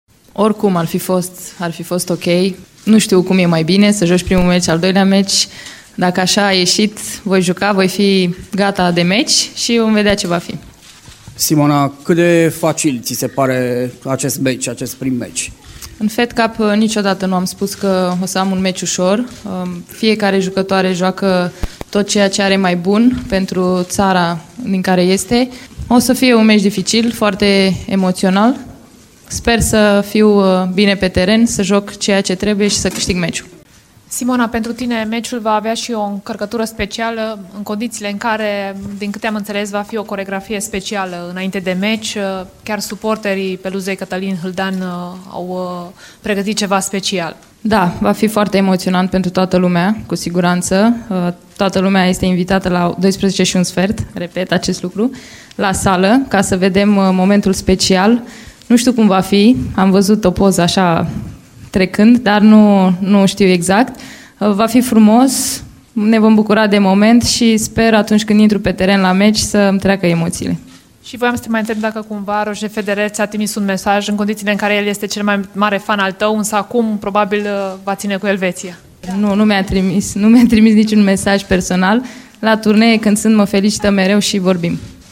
Simona Halep  a comentat faptul că dispută primul meci.
Întâlnirea dintre România şi Elveţia, meci din play-off-ul Grupei Mondiale, este reflectată de la fața locului de postul nostru de radio.
Halep-joaca-primul-meci.mp3